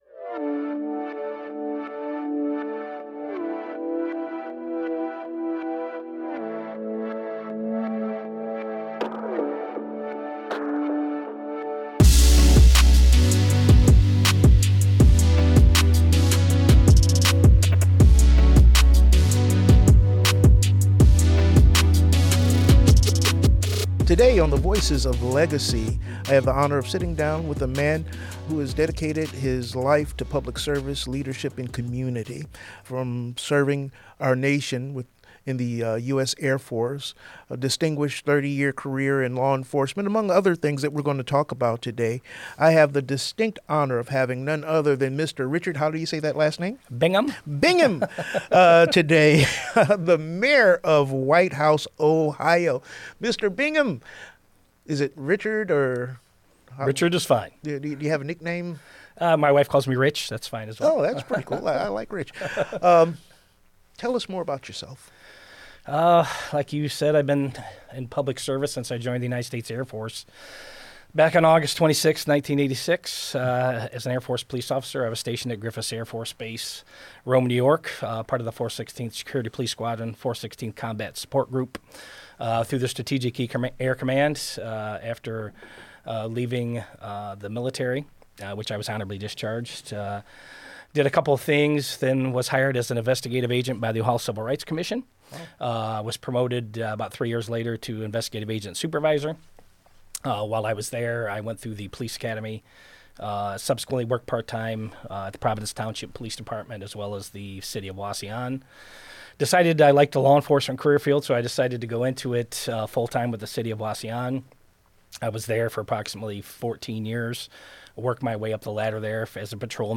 Leading with Legacy: A Conversation with Mayor Richard Bingham of Whitehouse, Ohio - WGTE Public Media